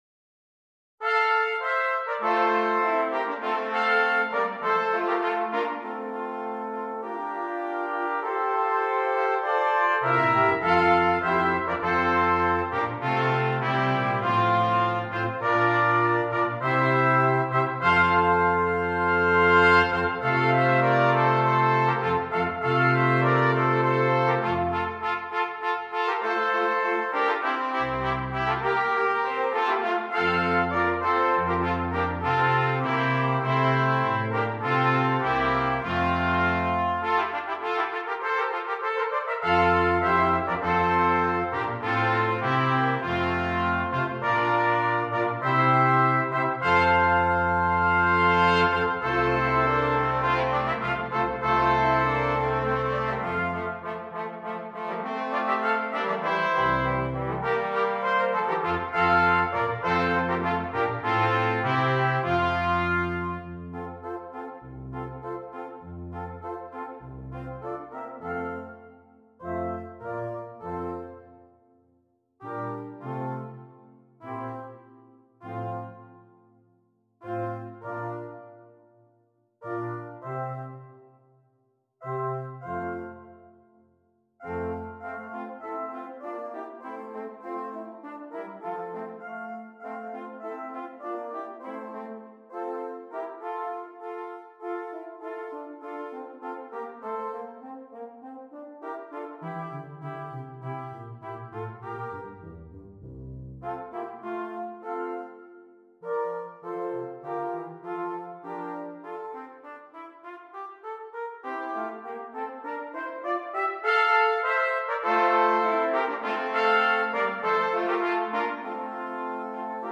Christmas
Brass Quintet
Traditional